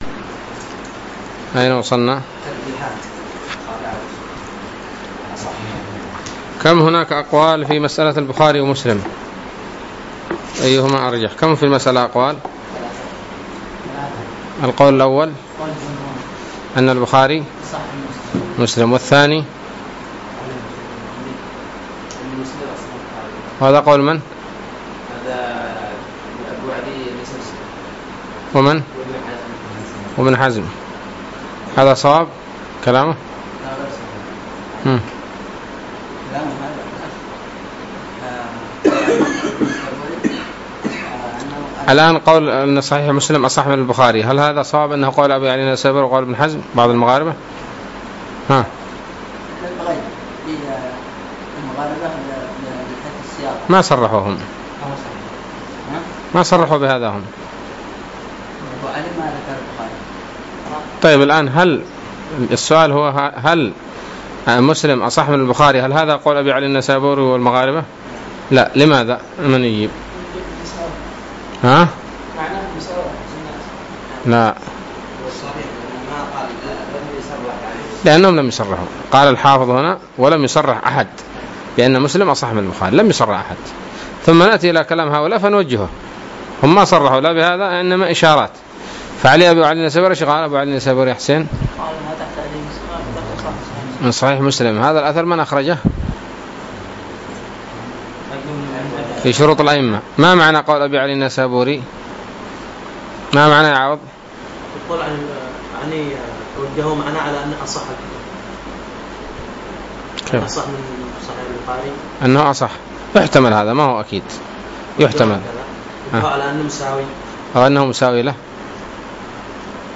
الدرس الثاني عشر من شرح نزهة النظر